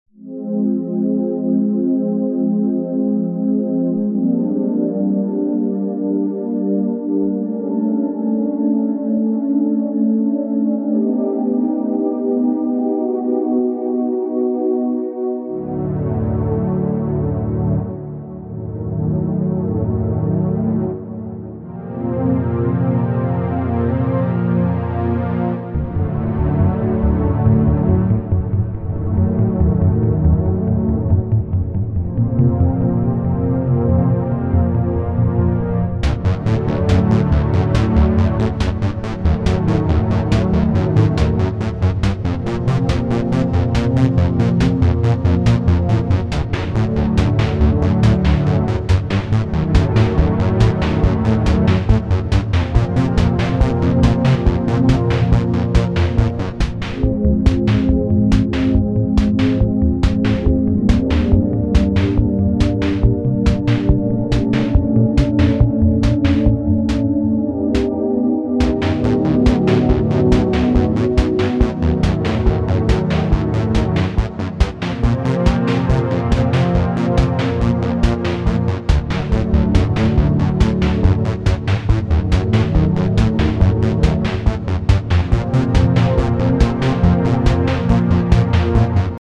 Made out of boredom.. Near the end I just put parts in random places.
Sounds suitable for a stealth-like mission...